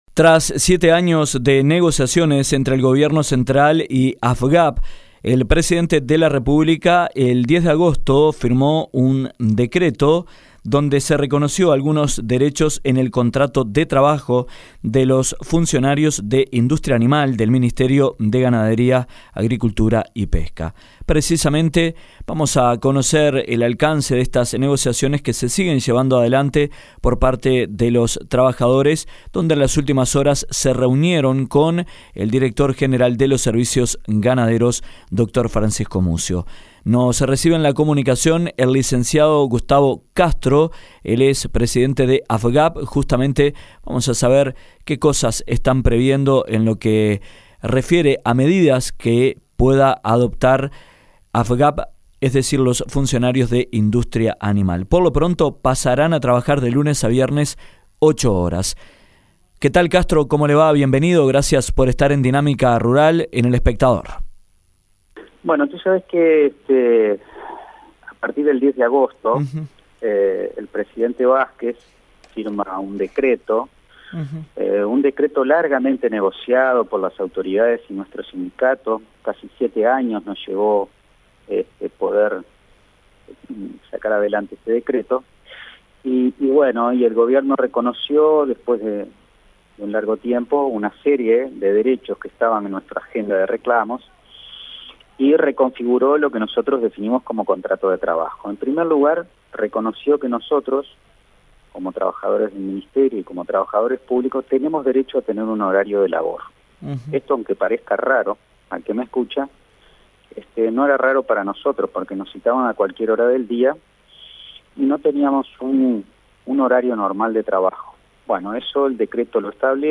En conversación con Dinámica Rural